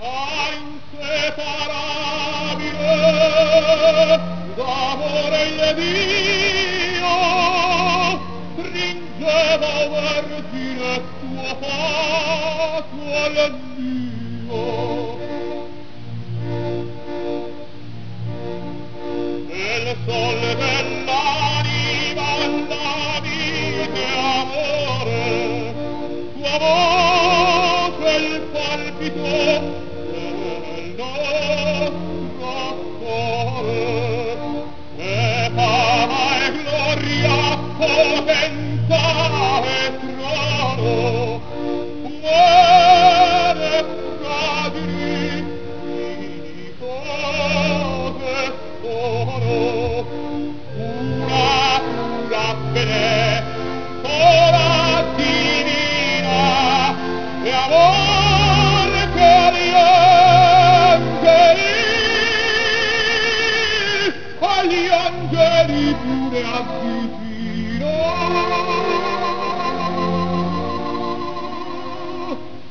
opera.wav